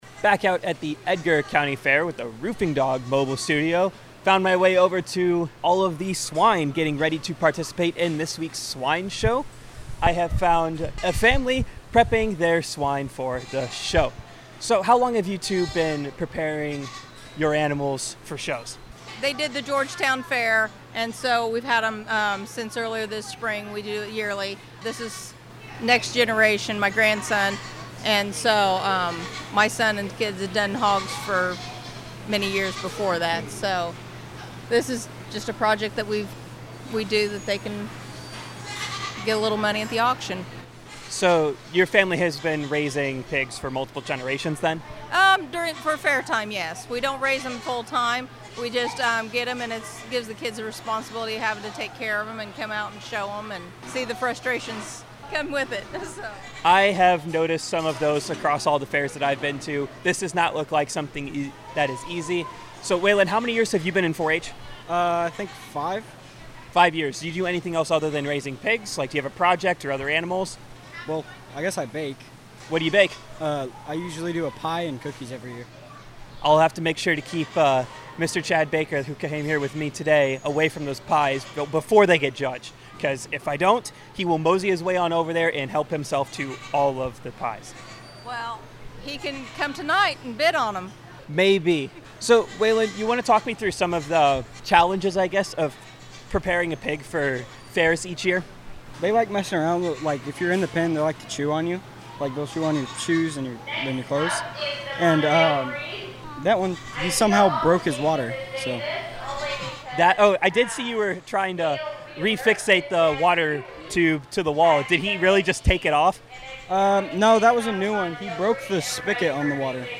WITY’s coverage of the Edgar County Fair from the Roofing Dog Mobile Studios is presented by Diepholz Auto Group, Precision Conservation Management, Longview Bank, Edgar County Farm Bureau, Ag Prospects, Prospect Bank, Nutrien Ag Solutions in Metcalf, Chrisman Farm Center, and First Farmers Bank & Trust.